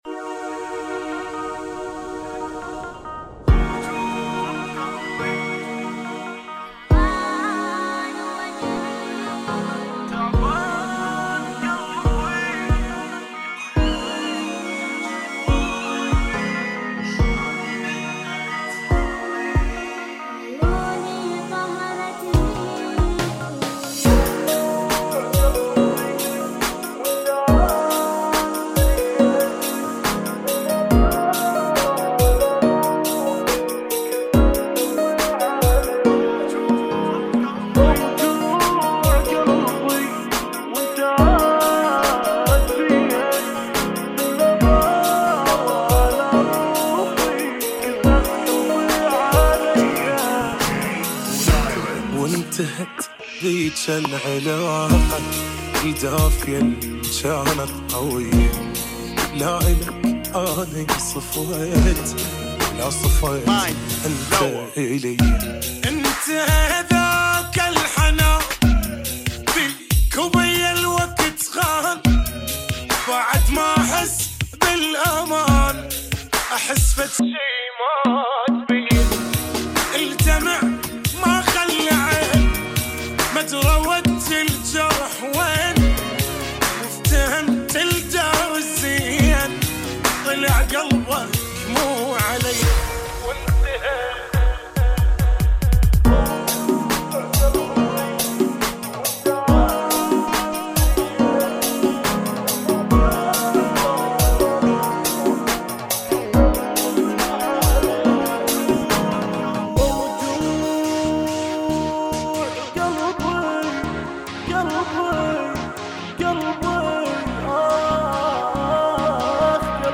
REMIX 2017